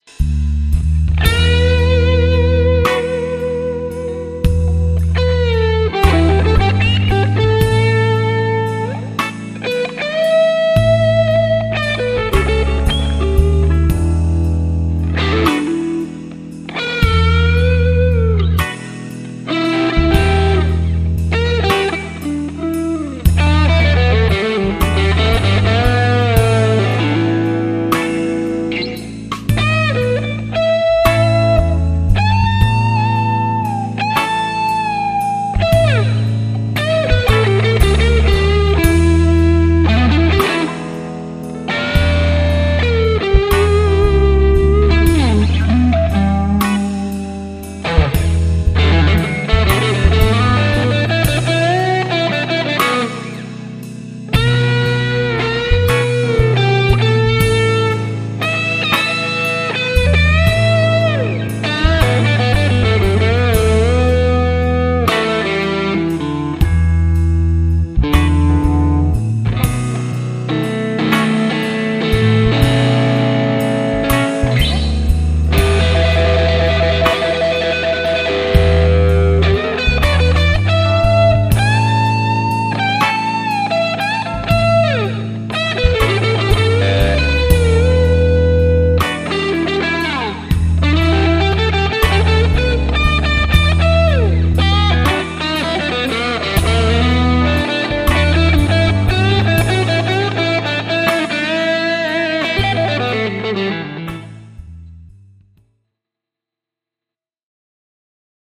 Aiheena E
- soita soolosi annetun taustan päälle